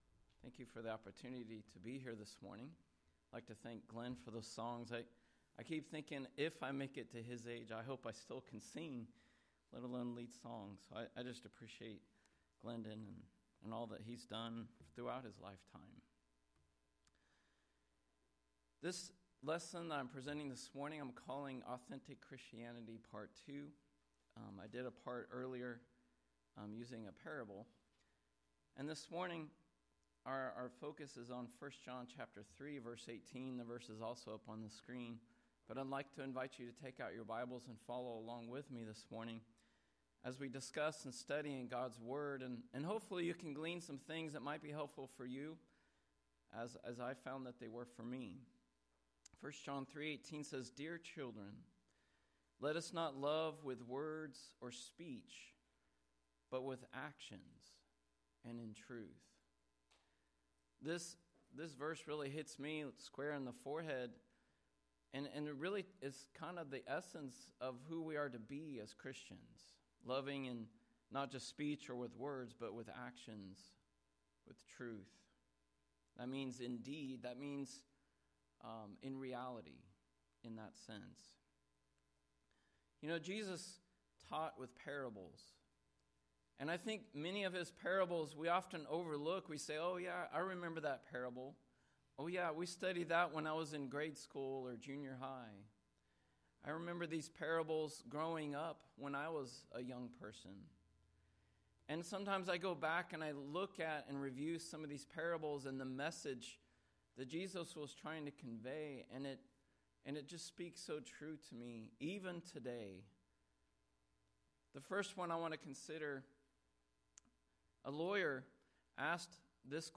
Lesson Recording: